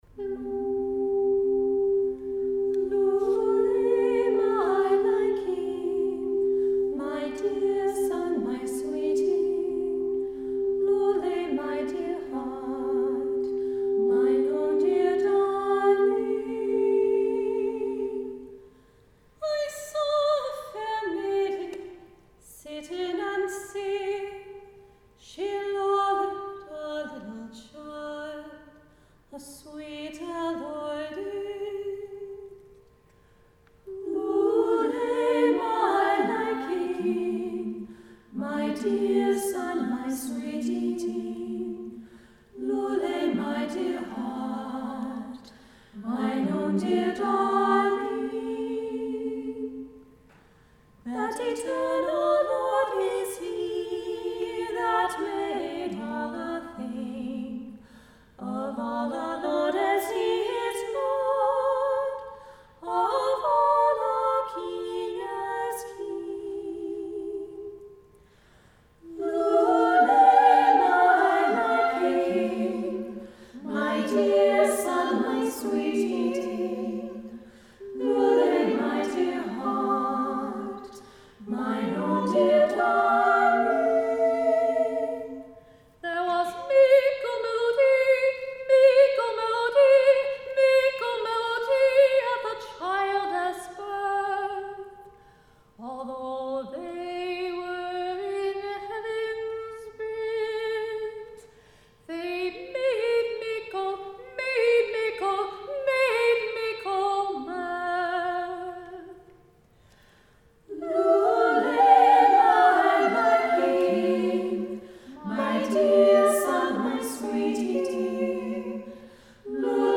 1:35 Fine Knacks for Ladies Greenleaf Singers 2:40 Come Again Sweet Love Greenleaf Singers 1:08 Fa Una Canzone Greenleaf Singers 1:57 Il Bianco e Dolce Cigno Greenleaf Singers 3:30 lullay my liking The women of Greenleaf Singers